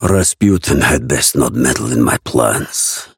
Raven voice line - Rasputin has best not meddle in my plans.